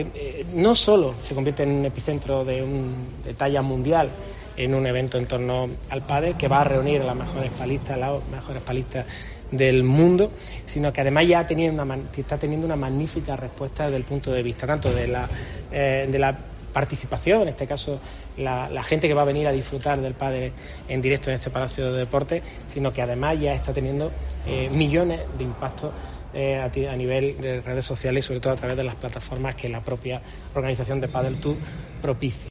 El alcalde habla del evento deportivo